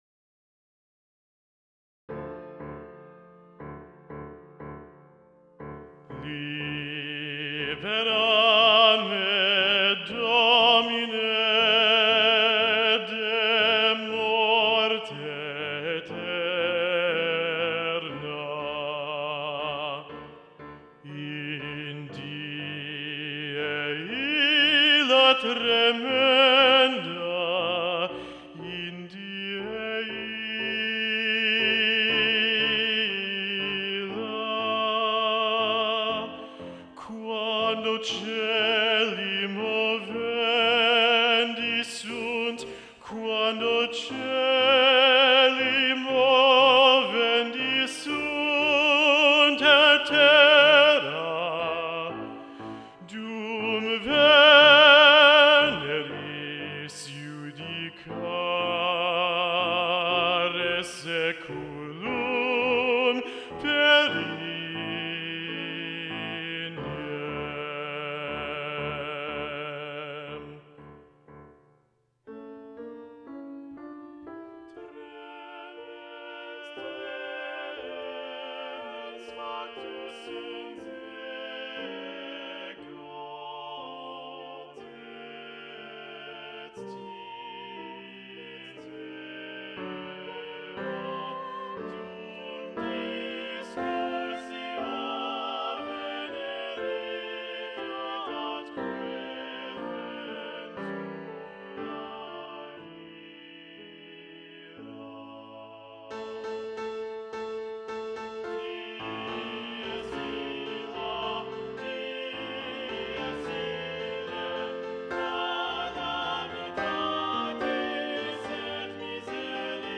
Index of /Rehearsal_Tracks/Faure_Requiem/Full Choir Access
Libera me (Requiem) - Baritone Solo Predominant - Gabriel Faure, ed. John Rutter.mp3